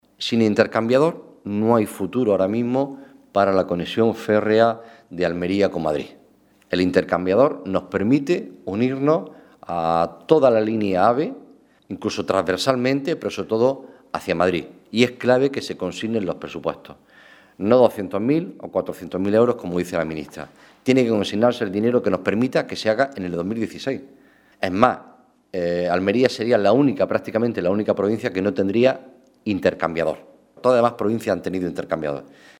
Rrueda de prensa que ha ofrecido el senador del PSOE de Almería Juan Carlos Pérez Navas